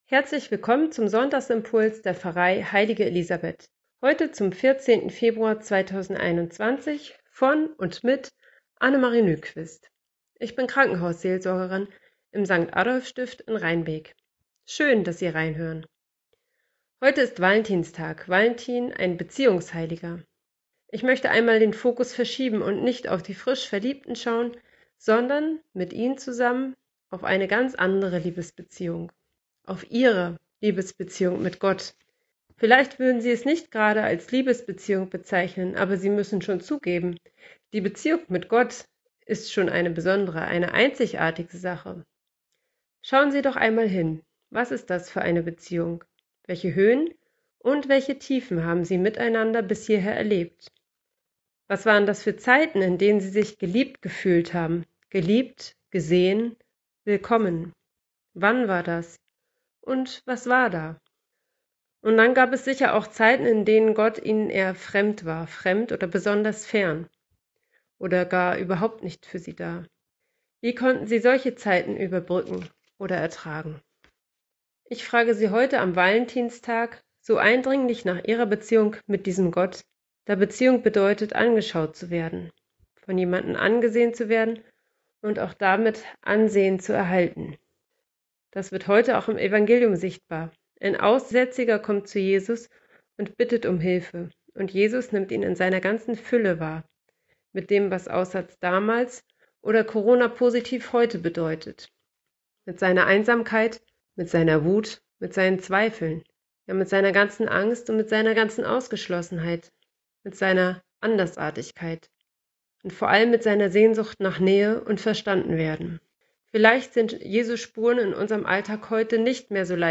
Im fünfundzwanzigsten Beitrag der Sonntagsimpulse spricht Krankenhausseelsorgerin